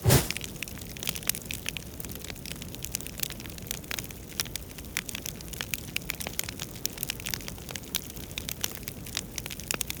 Light Torch with Starting Loop 2.ogg